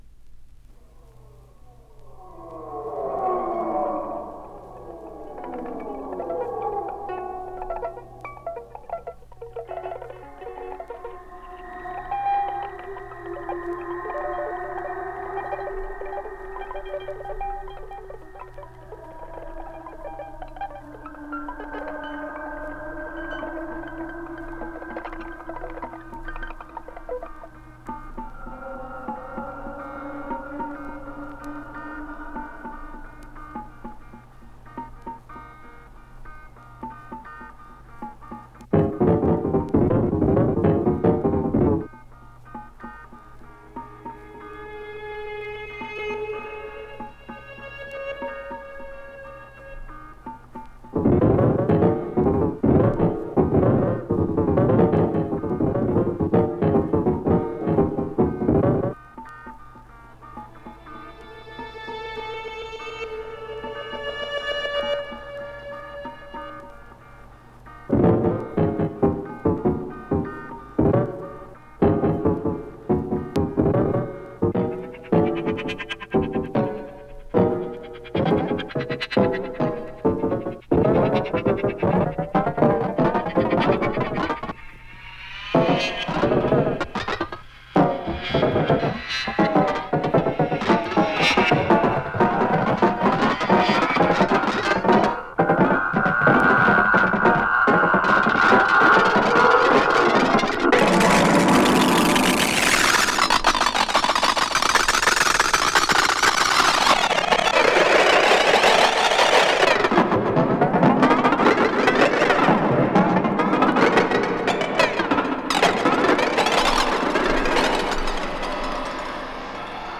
electronic and electroacoustic compositions